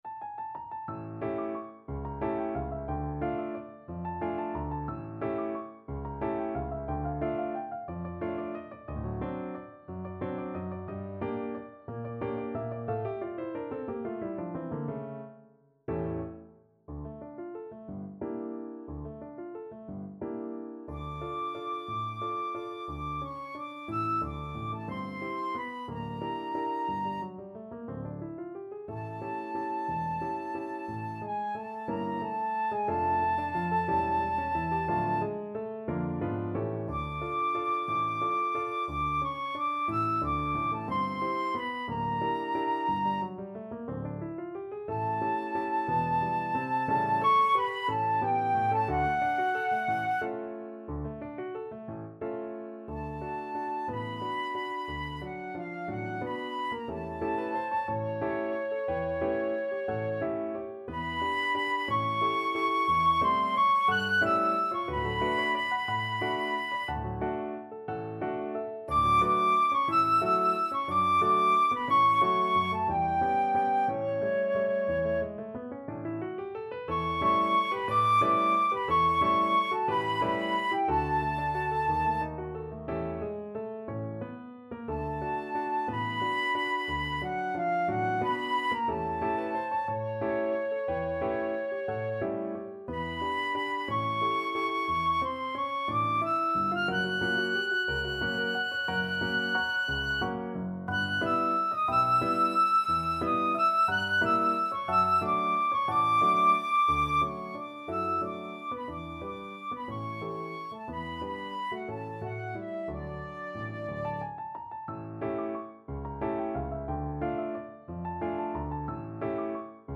3/4 (View more 3/4 Music)
Allegro movido =180 (View more music marked Allegro)
Classical (View more Classical Flute Music)
Mexican